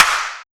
Gt Clap.wav